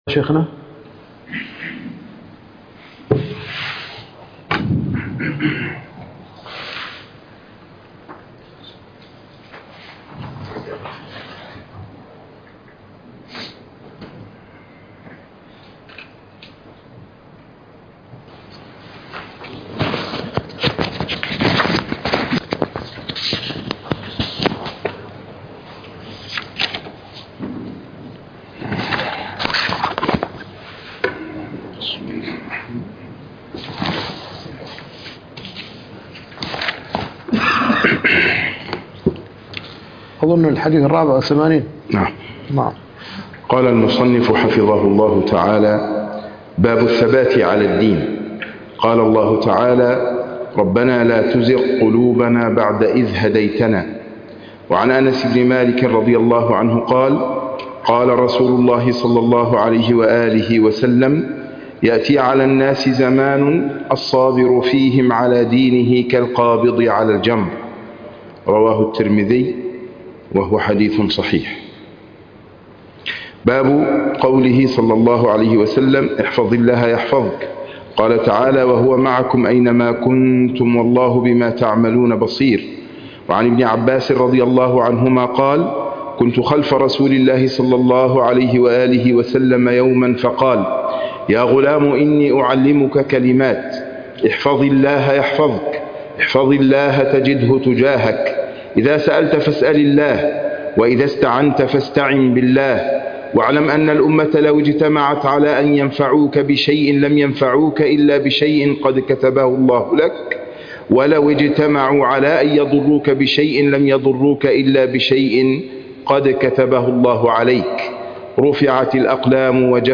الدرس السابع والثلاثون